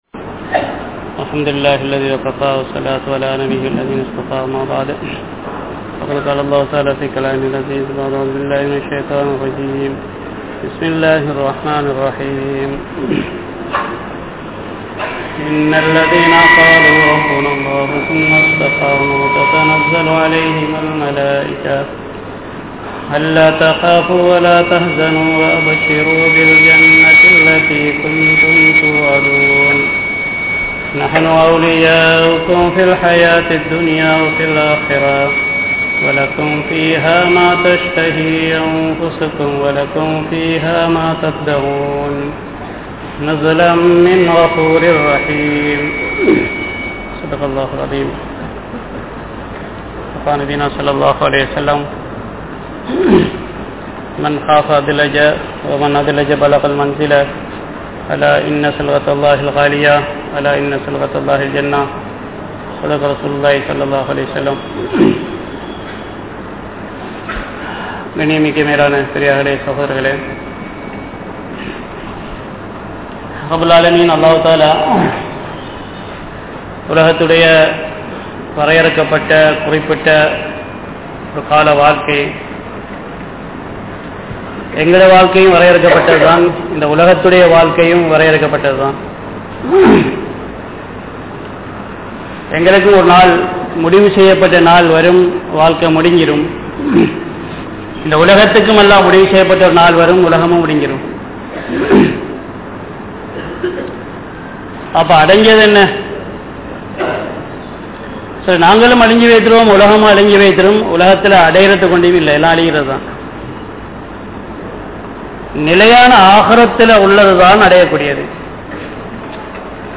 Marumaiyai Maranthu Vidaatheerhal (மறுமையை மறந்து விடாதீர்கள்) | Audio Bayans | All Ceylon Muslim Youth Community | Addalaichenai